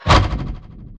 spawners_mobs_teleport.1.ogg